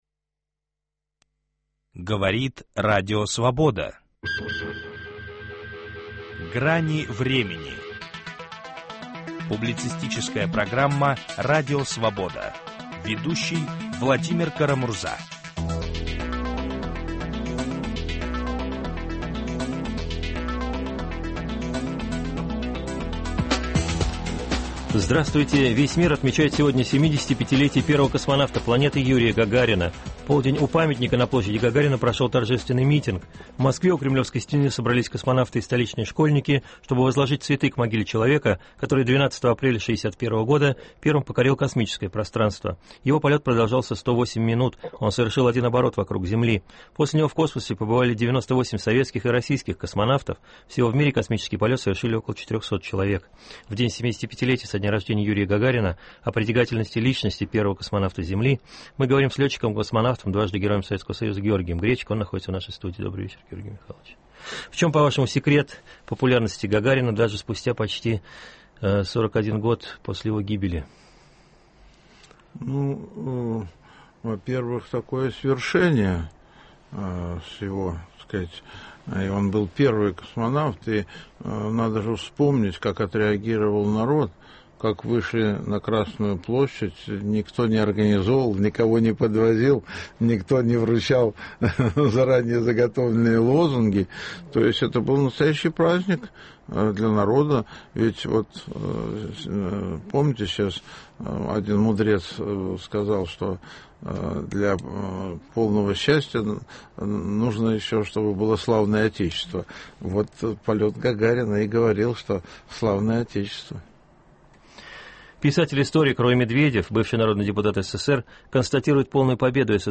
В день 75-летия со дня рождения Юрия Гагарина о современном состоянии российской космонавтики говорим с летчиком-космонавтом, дважды Героем Советского Союза Георгием Гречко.